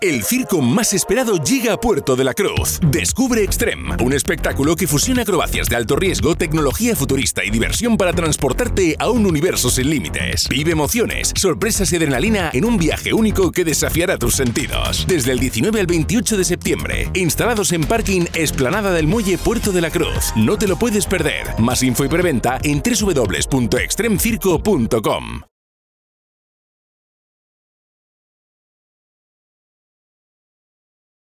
— Sonido en Radio Antena de Canarias.  Circo Extreme: